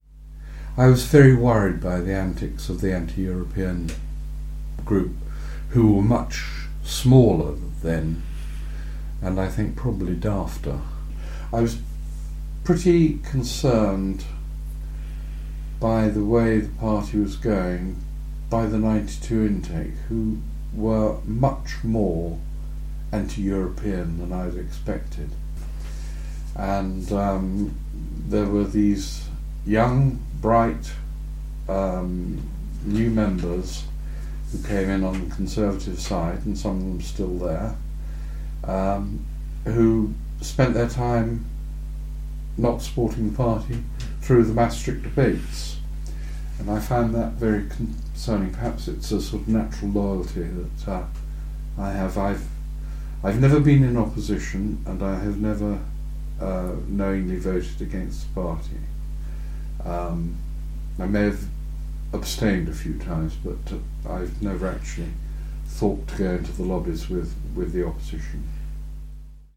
20th century history, Contemporary History, Diplomacy and International Relations, Factions, John Major, Modern, Oral history, Parties